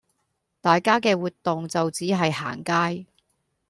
Гонконгский 816